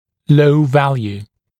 [ləu ‘væljuː][лоу ‘вэлйу:]низкое значение